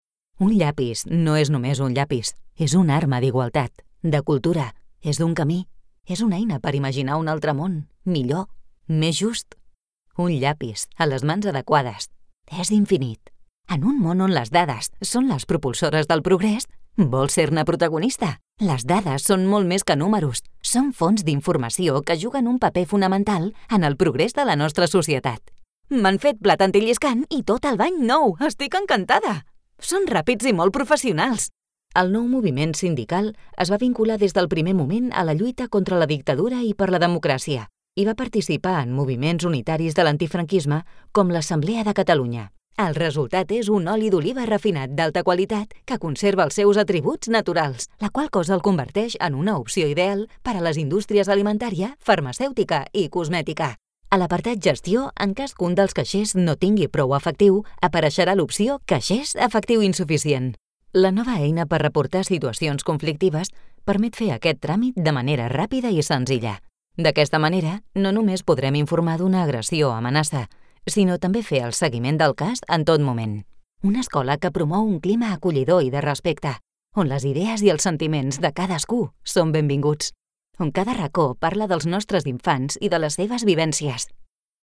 Vidéos d'entreprise
Corporate & professionnel  entreprise, finance, institutionnel
Neumann U87 Ai, Avalon V5, Apogee Duet, Pro Tools, cabine Studiobricks.